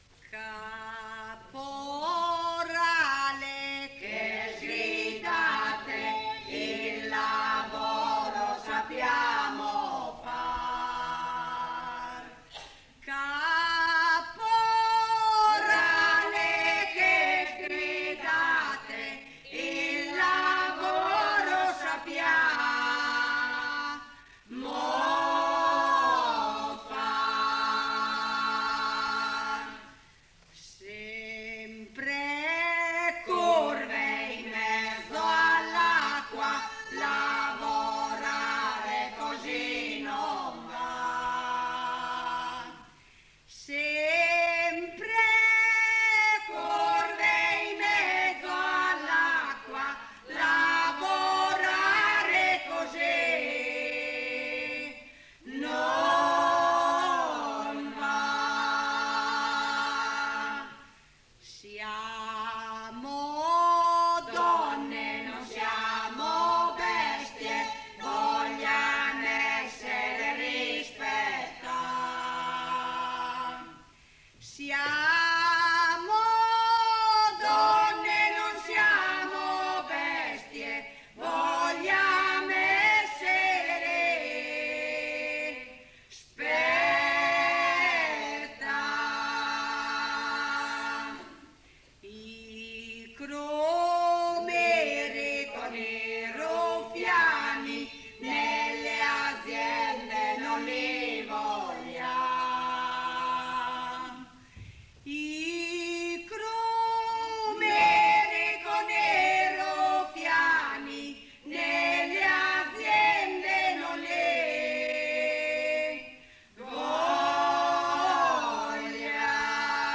Registrazioni dal vivo e in studio, 1982 circa